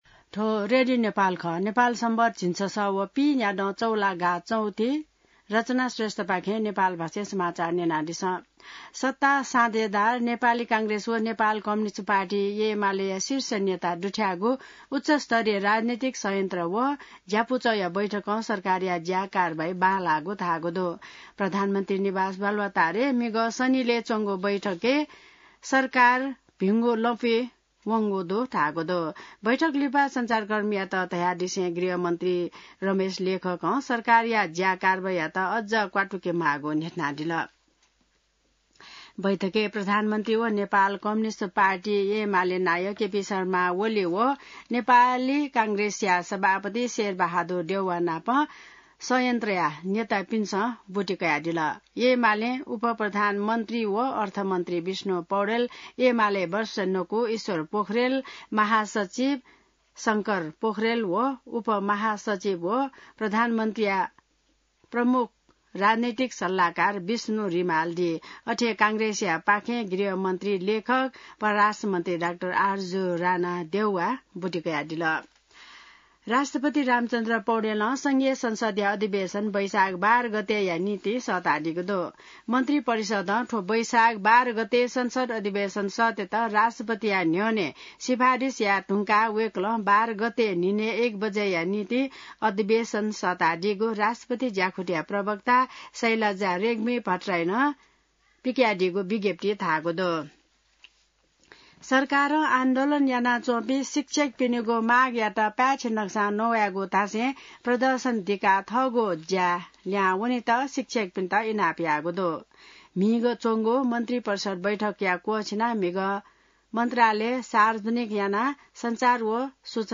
नेपाल भाषामा समाचार : ४ वैशाख , २०८२